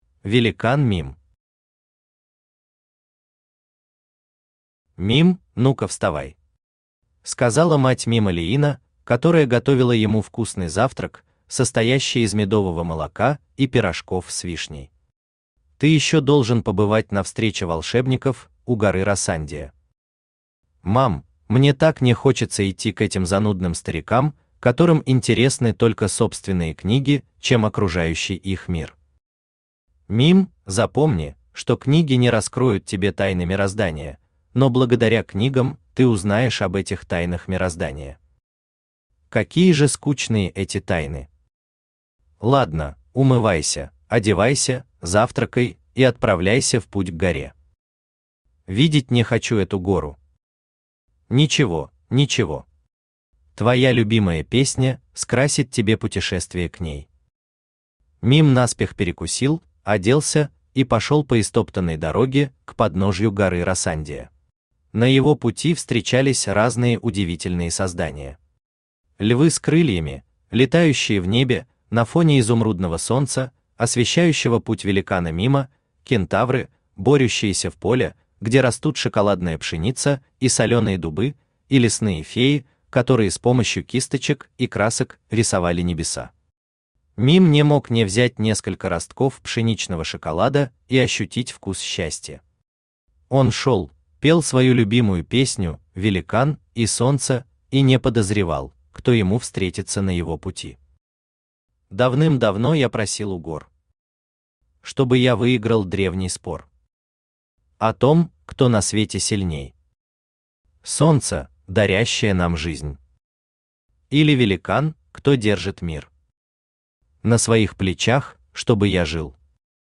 Сборник рассказов Автор Виталий Александрович Кириллов Читает аудиокнигу Авточтец ЛитРес.